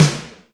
SLRSNARE.wav